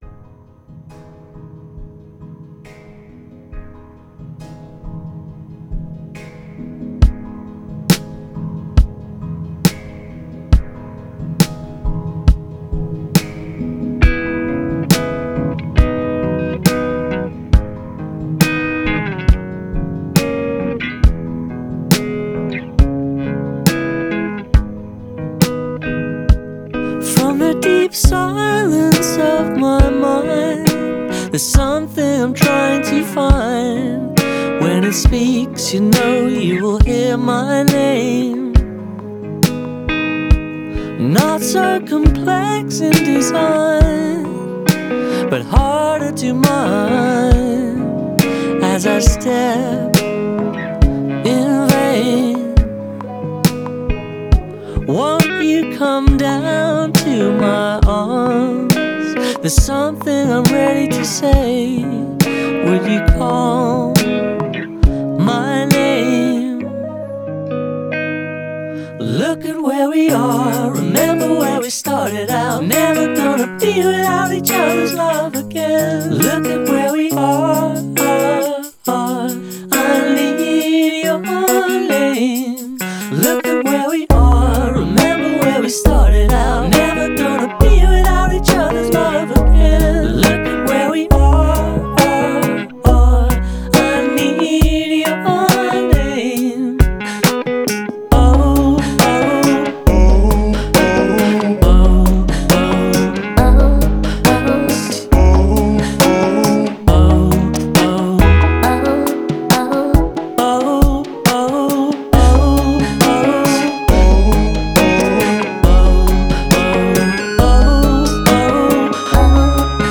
Today’s Indie
smoldering slow jam